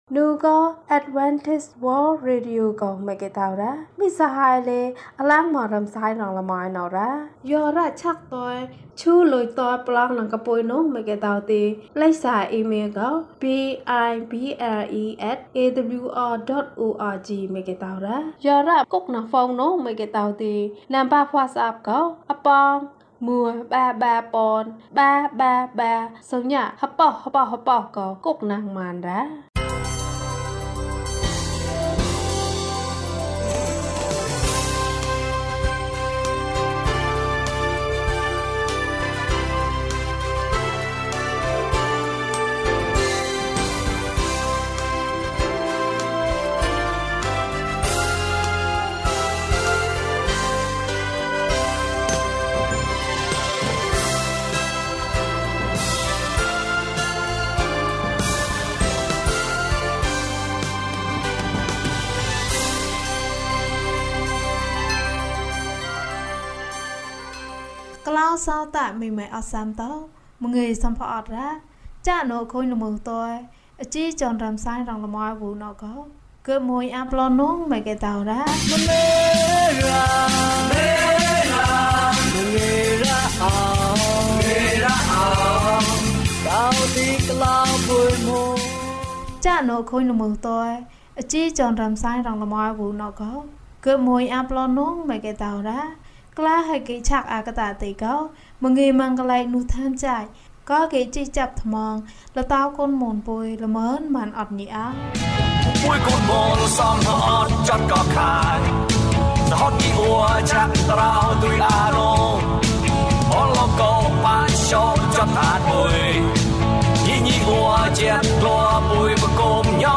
မိသားစုနှင့်အတူ ဘုရားသခင်။၀၁ ကျန်းမာခြင်းအကြောင်းအရာ။ ဓမ္မသီချင်း။ တရားဒေသနာ။